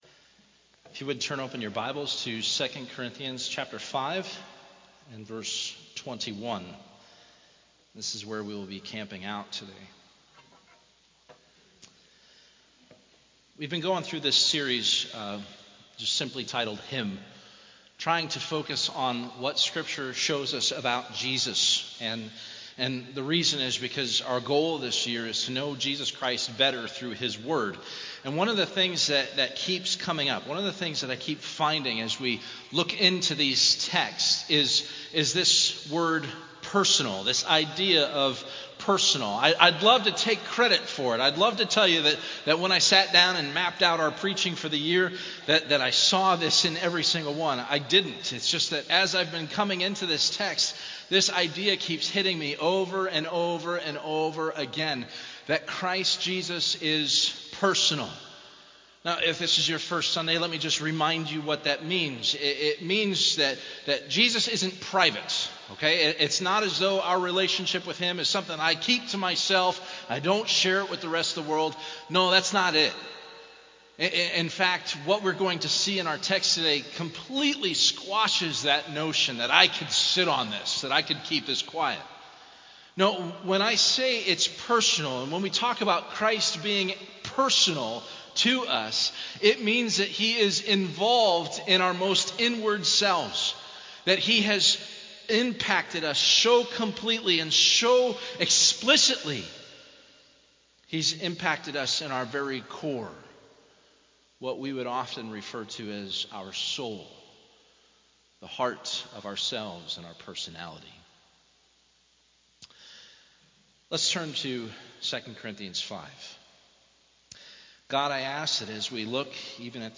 Of all the sermons in this series, this one focuses on a verse that seems to be the most personal because of how deeply it resonates within us who are being saved.